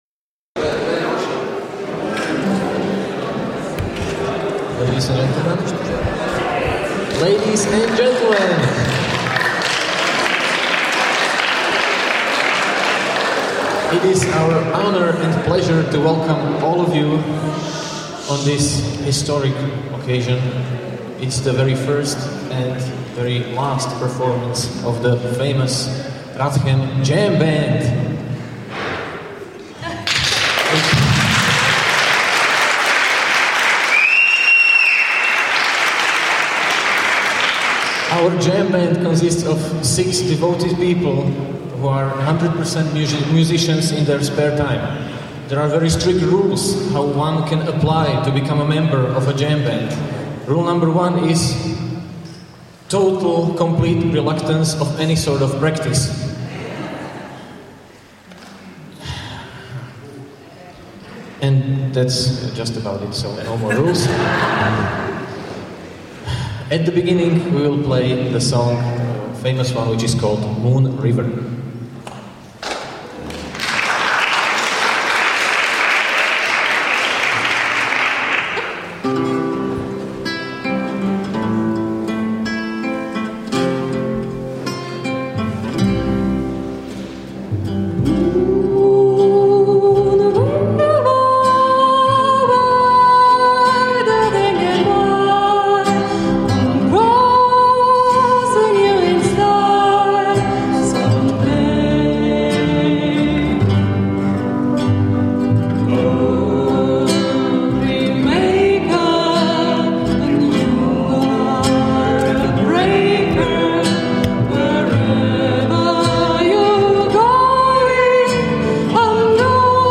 Following the huge success at the last meeting ( download the full audio recording or view a short video), Radiochemistry Jam Band Session will be organised again.
JamBandSession.mp3